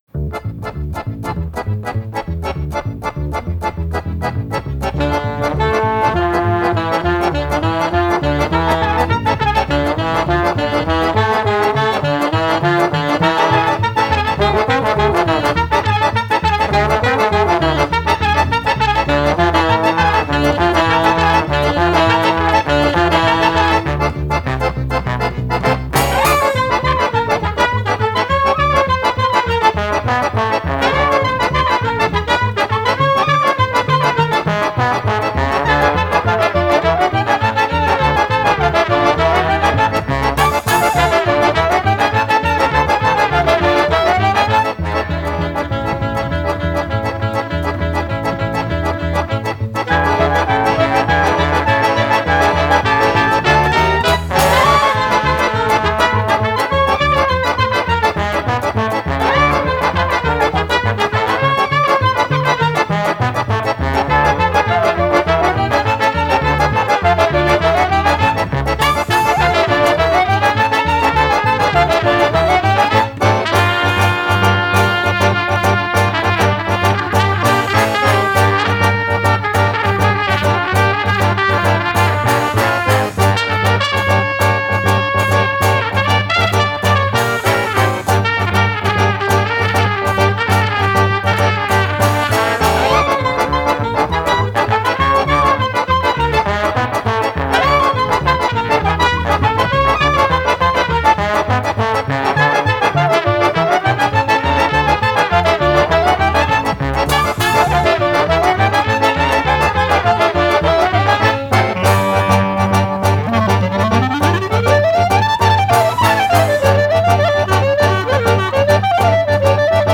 Genre: Klezmer, Balkan, Gypsy Jazz, Worldbeat